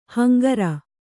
♪ hangara